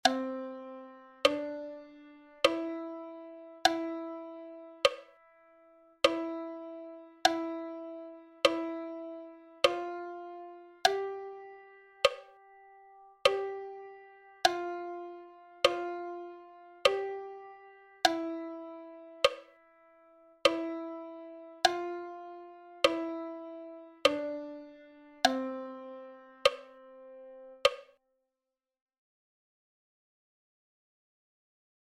Again you have a sound file combining the notes a metronome beat.